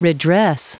Prononciation du mot redress en anglais (fichier audio)
Prononciation du mot : redress